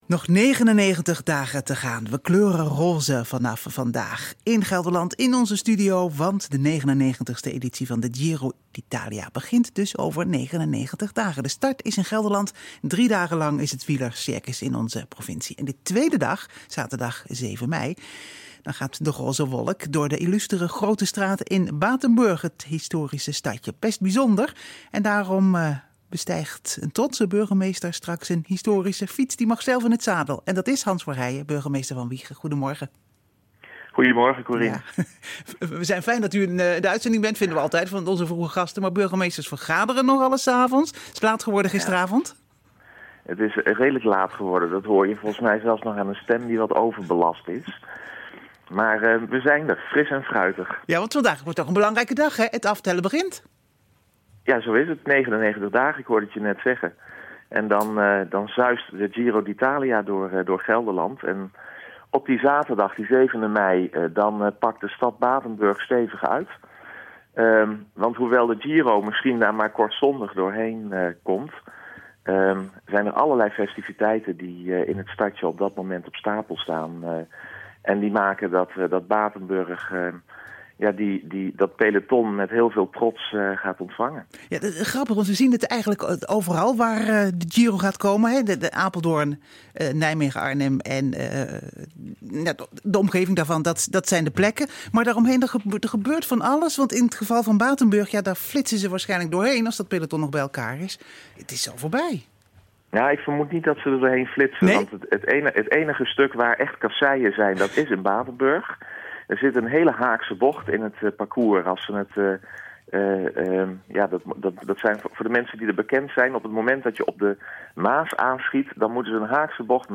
Hieronder het interview met Burgemeester Hans Verheijen van de Gemeente Wijchen op Omroep Gelderland op donderdag 28 januari 2016
interview-Burgemeester-Hans-Verheijen-over-de-aftrap-giro-in-Batenburg.mp3